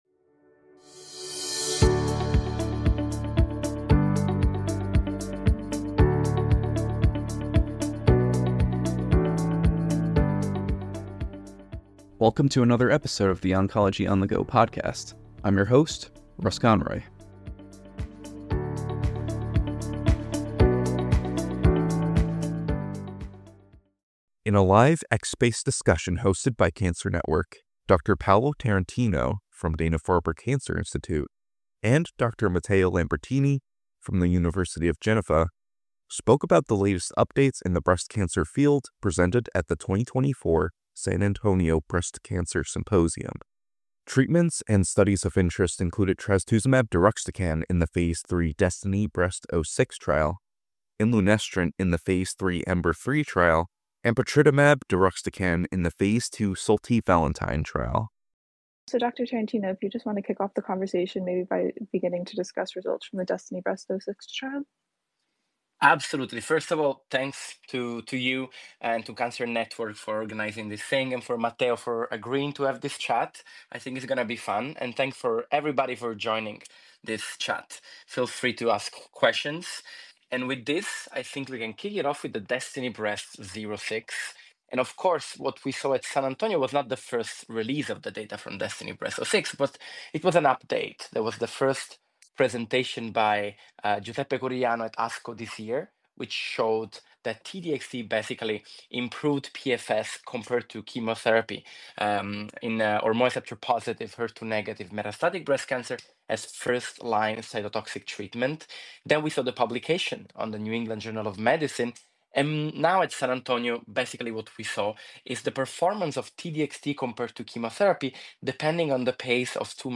Oncology On The Go is a weekly podcast that talks to authors and experts to thoroughly examine featured articles in the journal ONCOLOGY and review other challenging treatment scenarios in the cancer field from a multidisciplinary perspective.